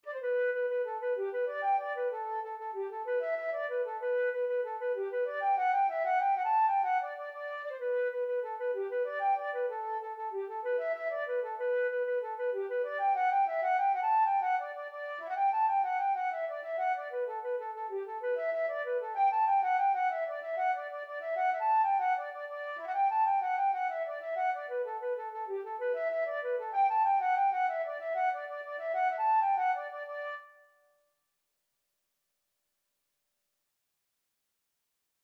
G major (Sounding Pitch) (View more G major Music for Flute )
6/8 (View more 6/8 Music)
G5-A6
Instrument:
Traditional (View more Traditional Flute Music)
Flute Sheet Music